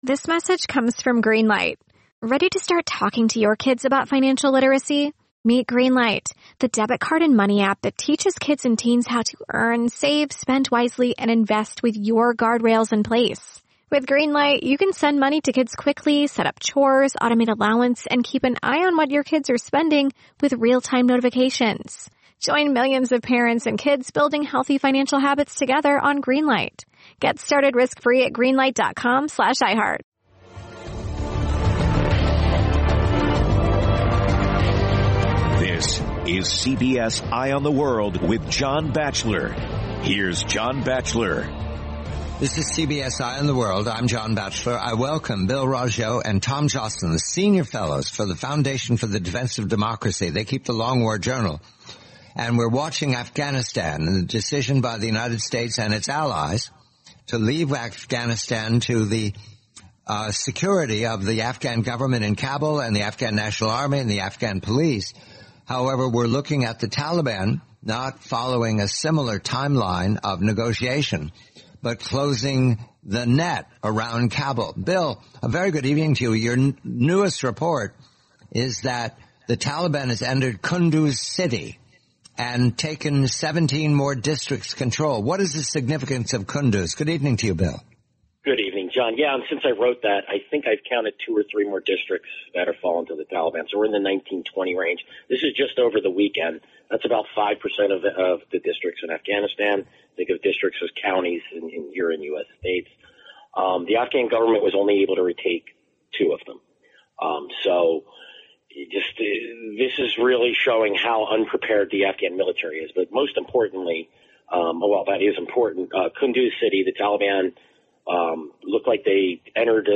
The complete, nineteen minute-interview, June 21, 2021.